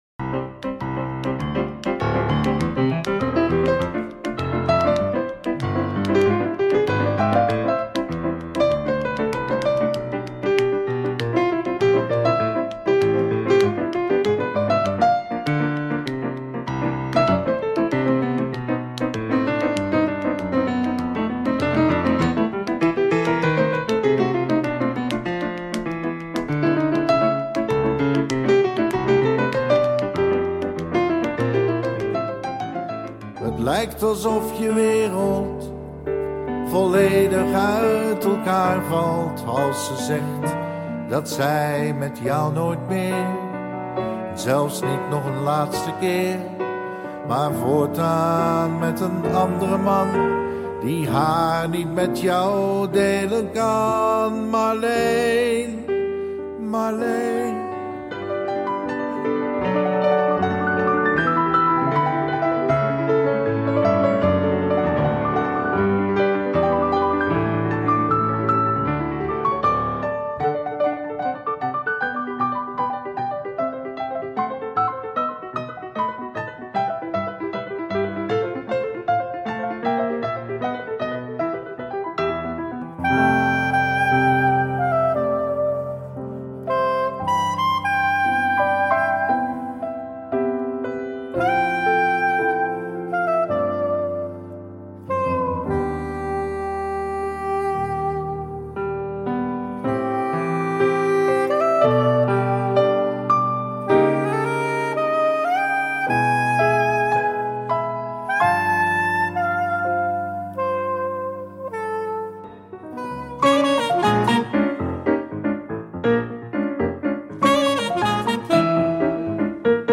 Een album vol piano pareltjes in bijzondere bewerkingen.
Met af en toe wat zang.
sax.